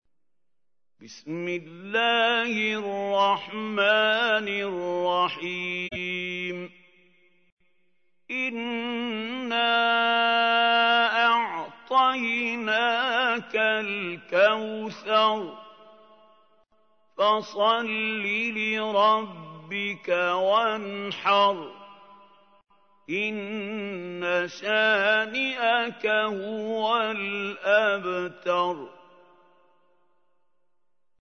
تحميل : 108. سورة الكوثر / القارئ محمود خليل الحصري / القرآن الكريم / موقع يا حسين